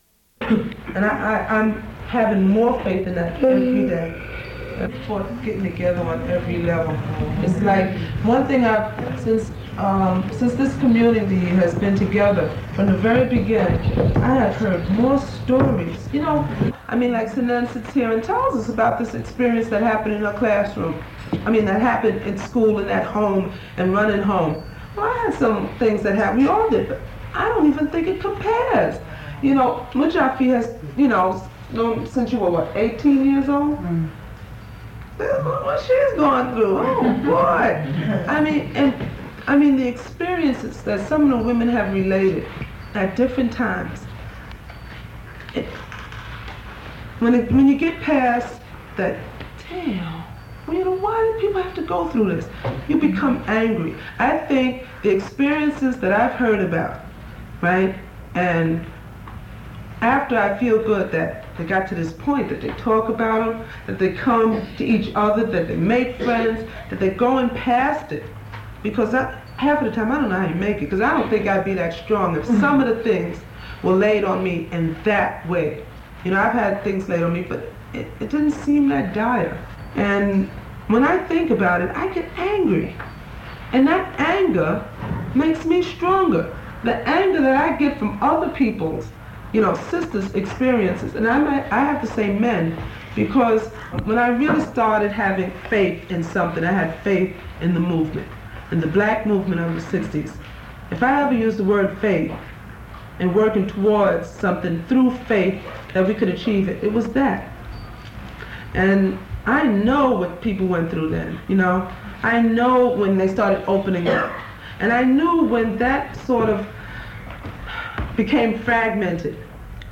Download File Download File Title The Lesbian Show - Kwanza Subject Kwanzaa Description Recordings of a group discussion around the 7 principles of Kwanzaa, particularly in regards to community and faith, interspersed with singing of Kwanzaa songs.